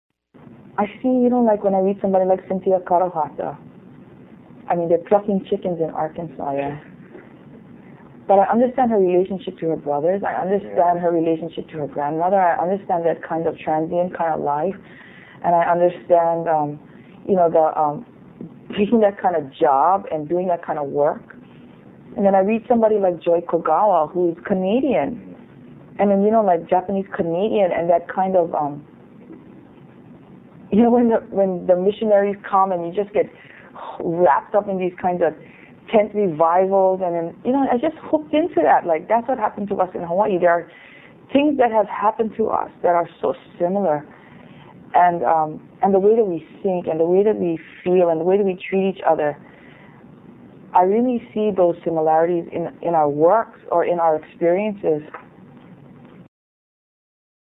Q&A with Lois-Ann Yamanaka